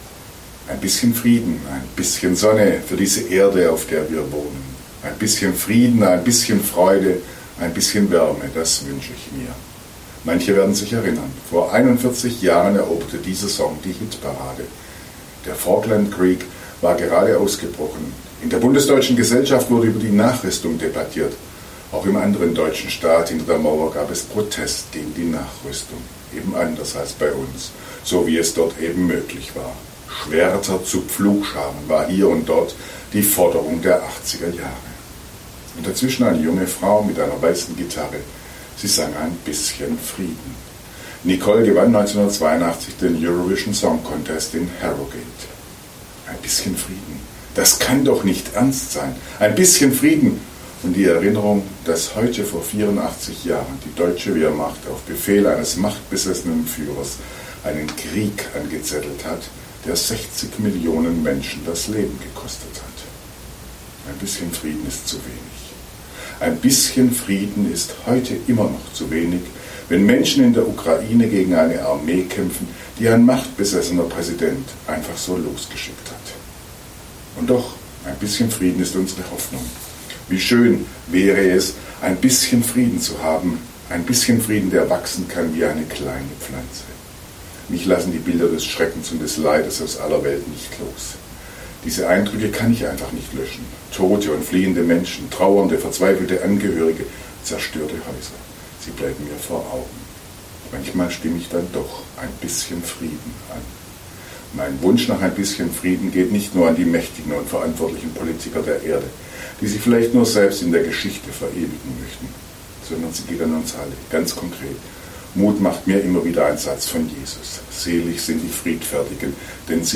Radioandacht vom 1. September